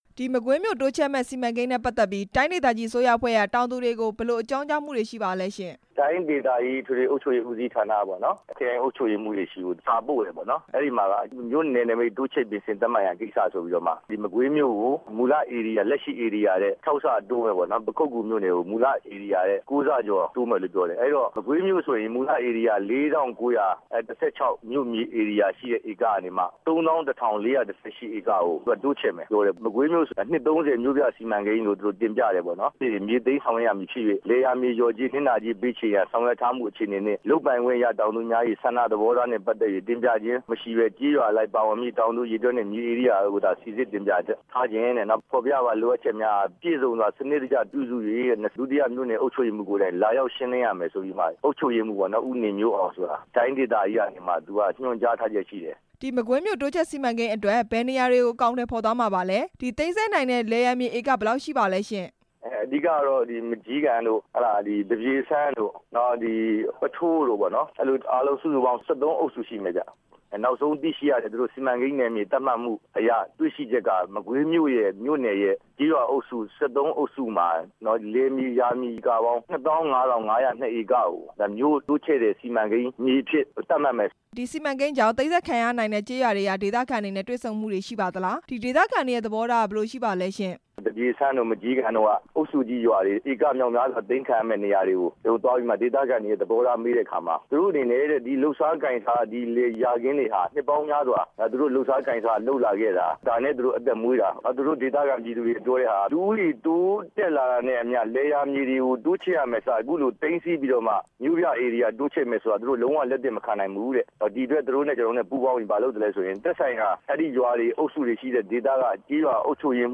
မကွေးမြို့သစ်စီမံကိန်းကို ကျေးရွာ ၃ဝ ကျော် ကန့်ကွက်နေတဲ့အကြောင်း မေးမြန်း ချက်